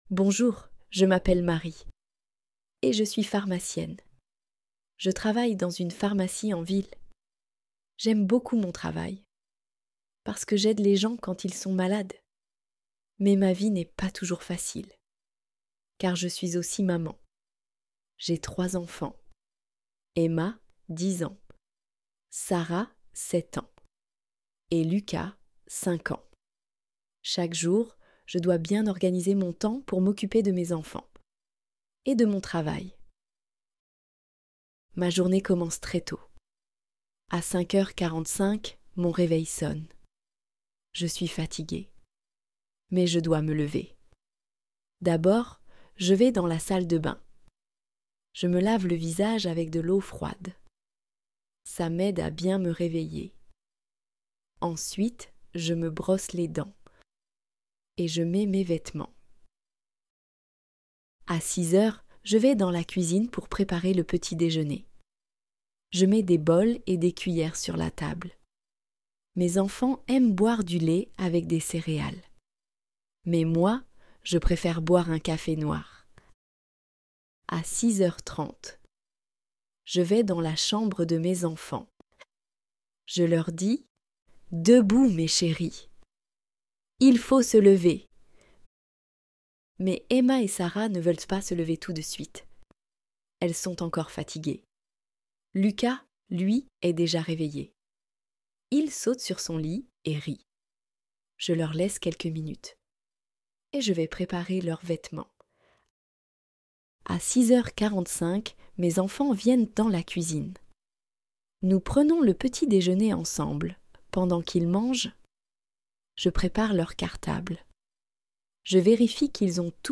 Histoire en Français